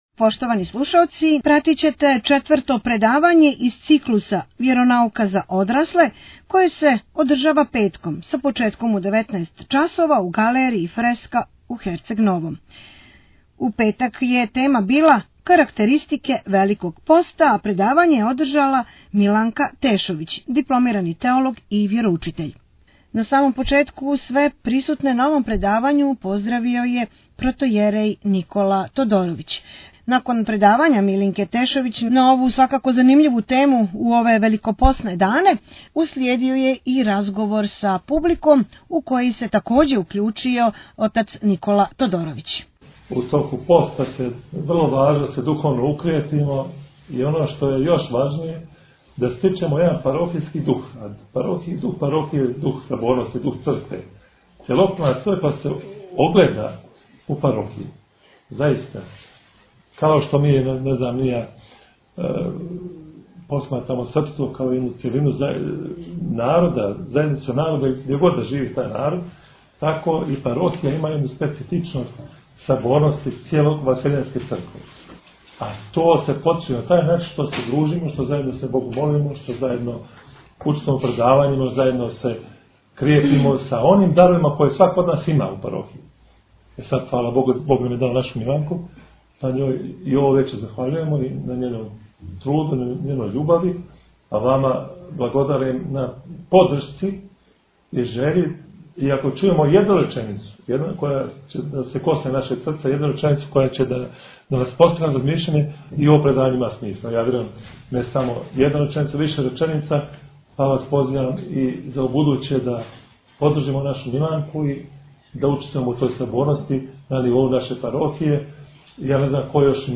У Недјељу православља одслужена Литургија у цркви Светог Ђорђа у Подгорици